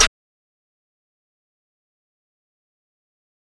Snare (Green & Purple).wav